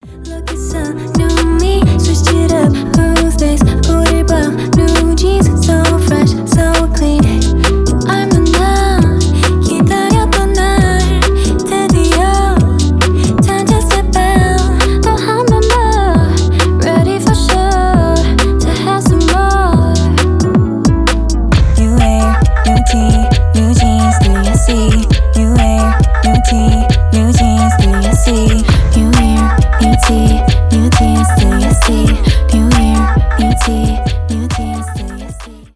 uk garage
jersey club , k-pop